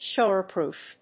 アクセント・音節shówer・pròof